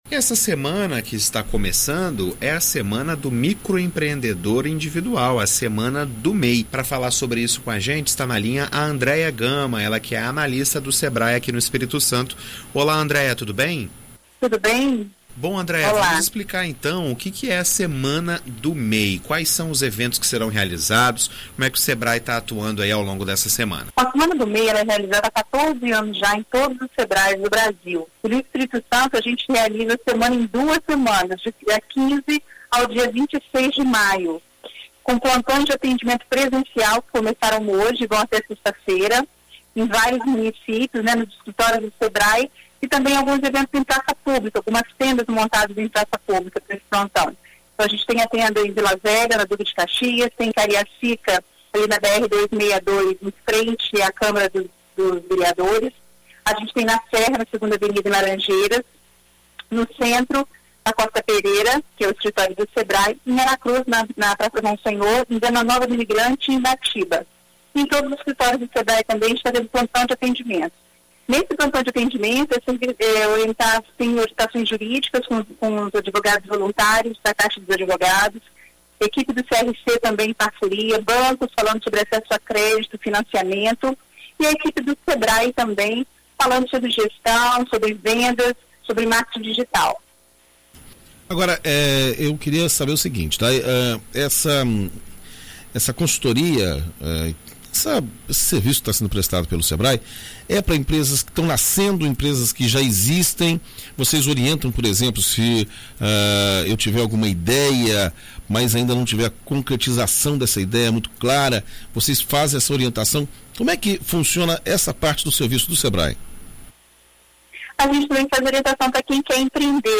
Em entrevista a BandNews FM ES nesta segunda-feira